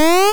jump.wav